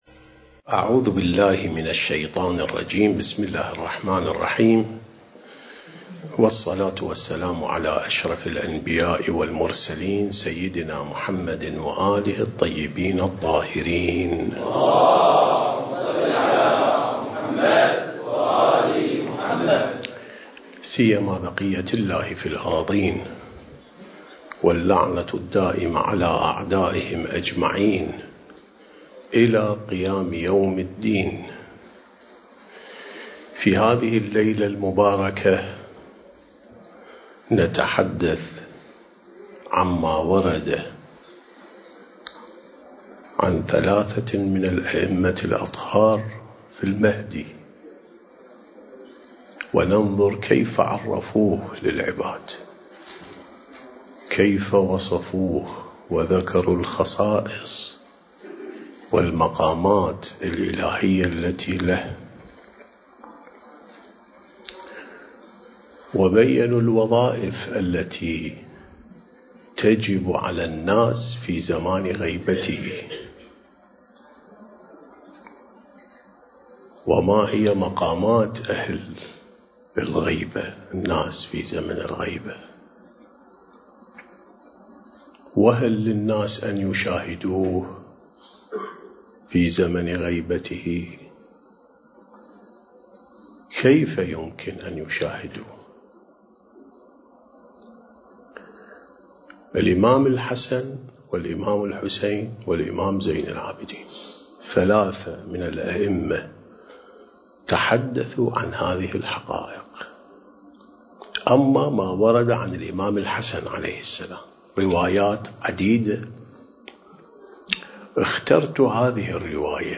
سلسلة محاضرات: الإعداد الربّاني للغيبة والظهور (8)